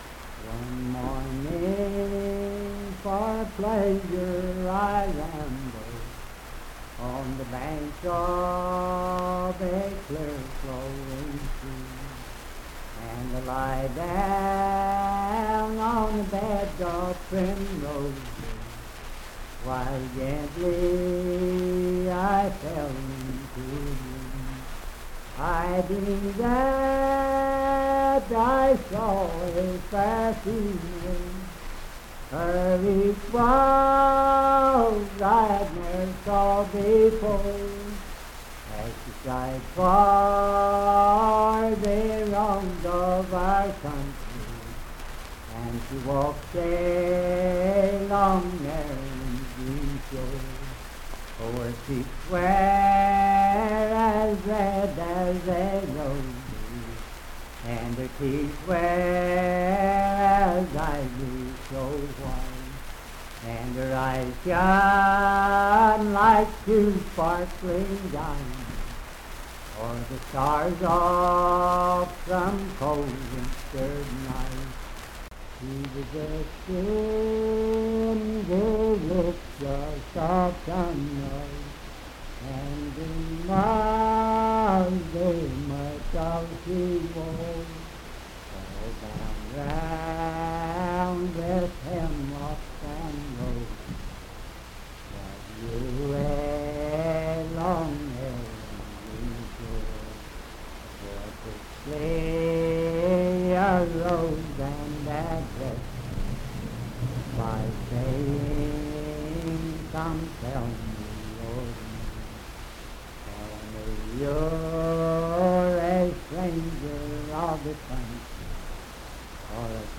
Unaccompanied vocal music and folktales
Voice (sung)
Wood County (W. Va.), Parkersburg (W. Va.)